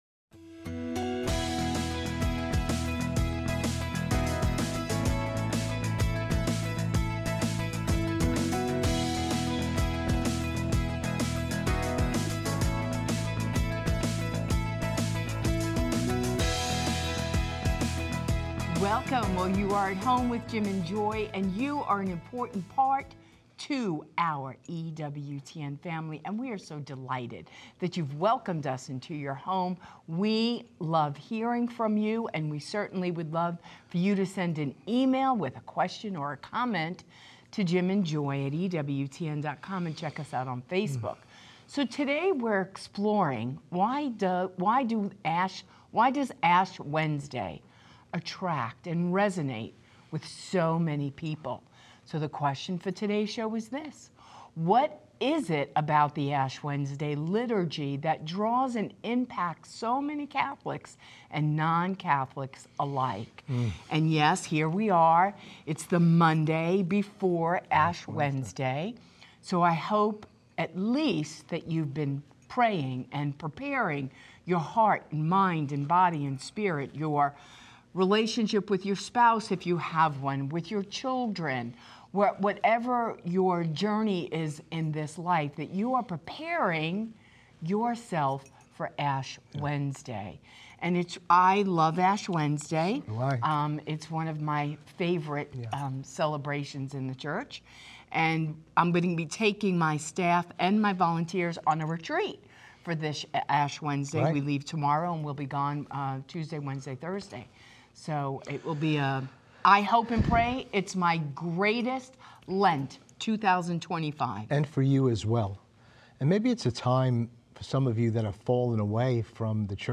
CALL-IN SHOW